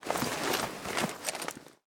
inv_open.ogg